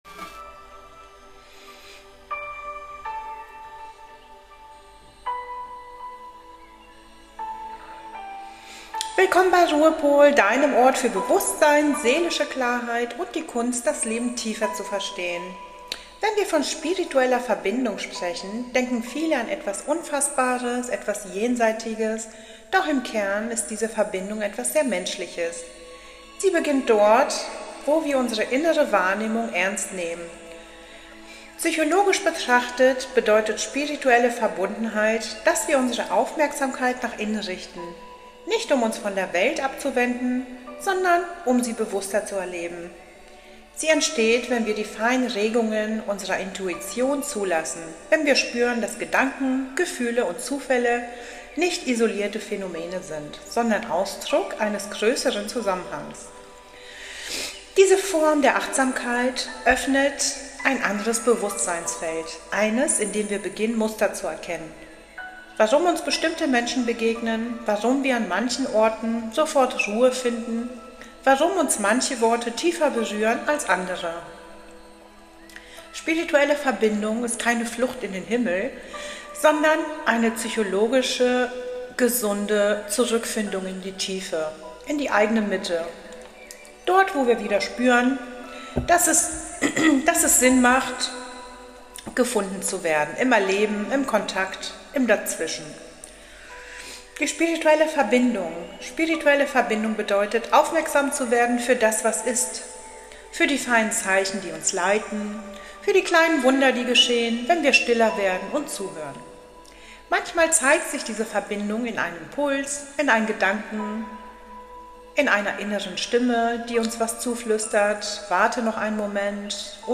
🪶 Im zweiten Teil erwartet dich eine sanfte Abendmeditation – „Eingehüllt im Universum“. Eine Reise in die Stille zwischen den Sternen – zum Loslassen, Einschlafen und Wiederfinden deiner inneren Ruhe.